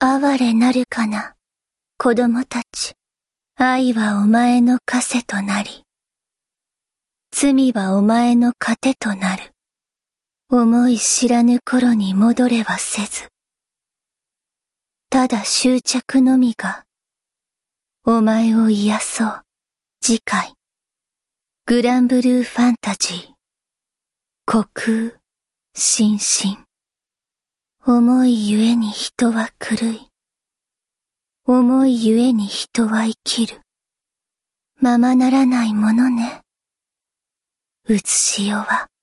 Voiced Trailer